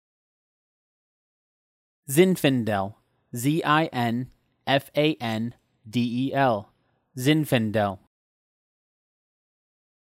Editor’s Note: This post contains user-submitted pronunciations.
zin-fuh n-del